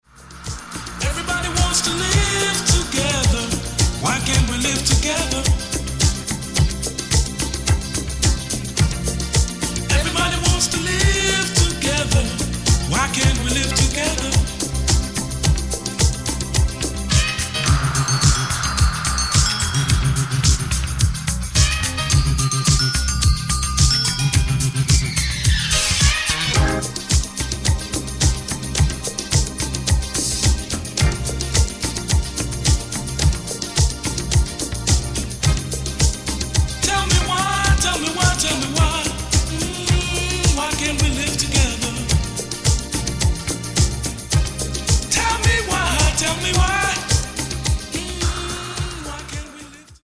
Genere:   Disco | Electro Funky